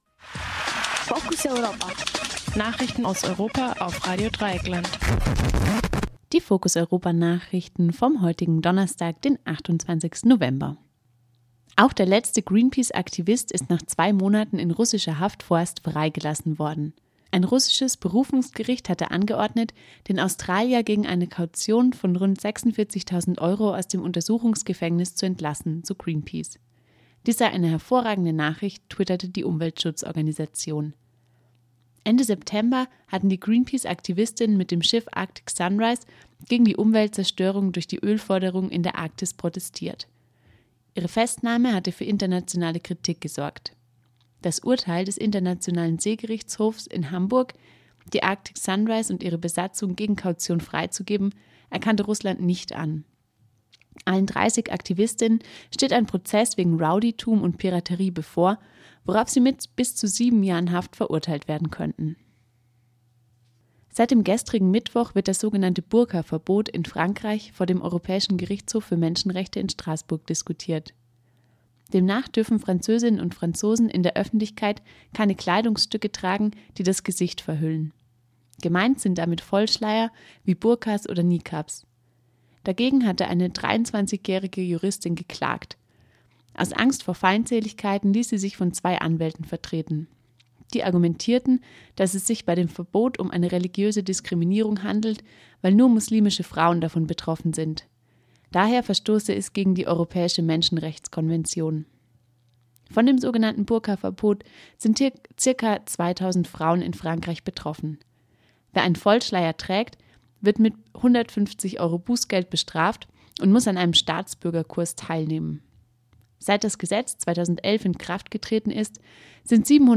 Focus Europa Nachrichten vom Donnerstag, den 28. November - 18 Uhr